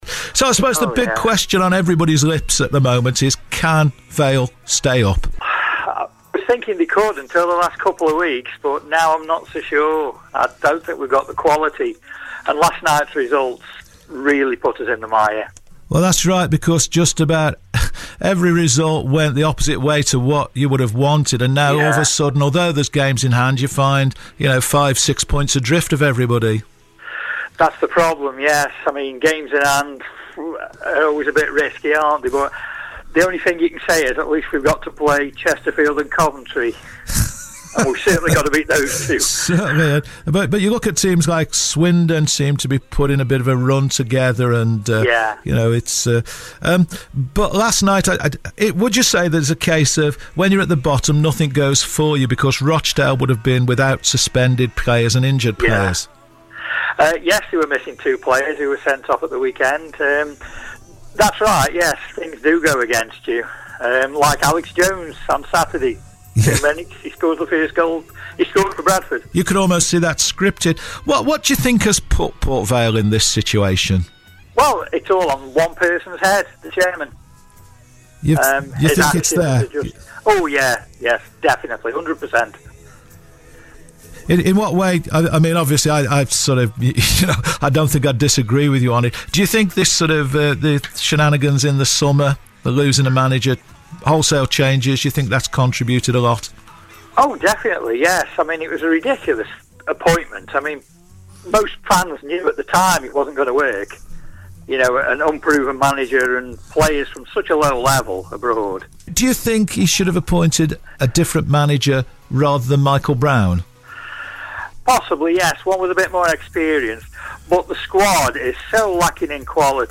First broadcast on Cross Rhythms City Radio 101.8FM on 1 March 2017.